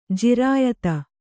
♪ jirāyata